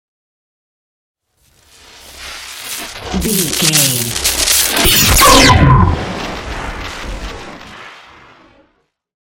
Whoosh to hit electricity large
Sound Effects
dark
high tech
intense
tension
woosh to hit